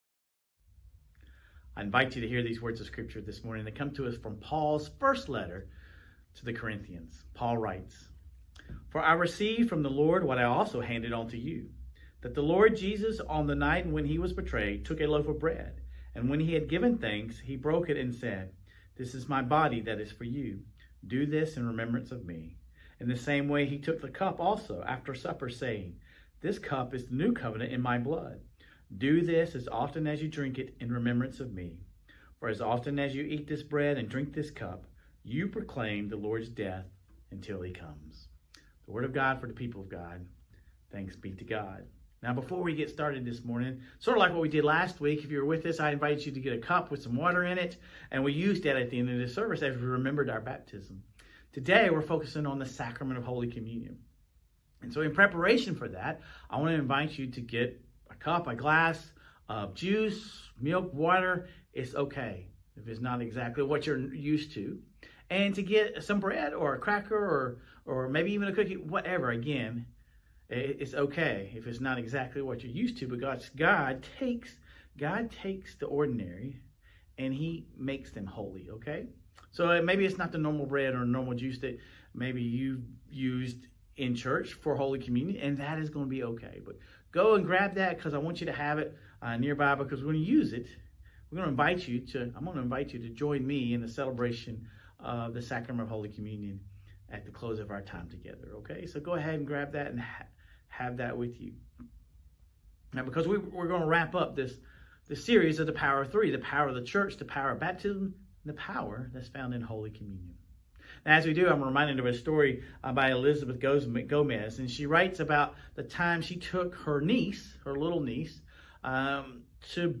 Sermon Reflections: What role does communion play in our relationship with God and with others?